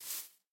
grass4.mp3